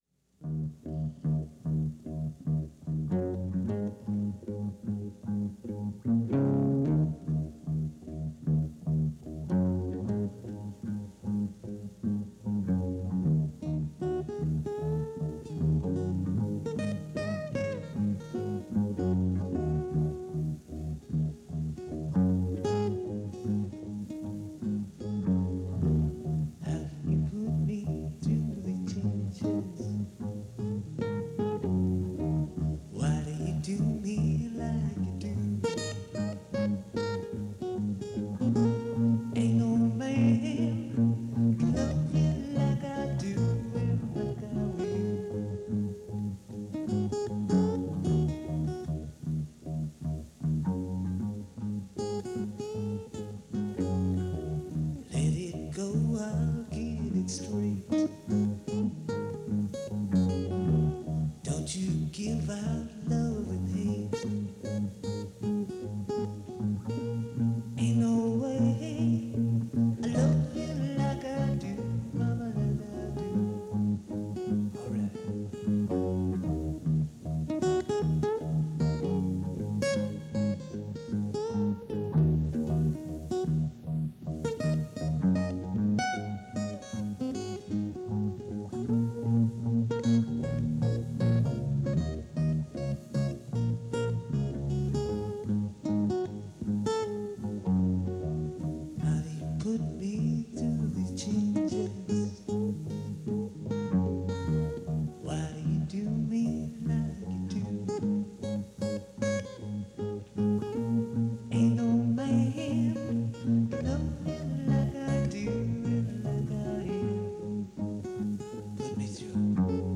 Category Rock/Pop
Studio/Live Both
guitar and vocals
bass and lead guitars and vocals
Lead and backing vocals
percussion and backing vocals
Recorded in Peoria and Washington, Il. 1977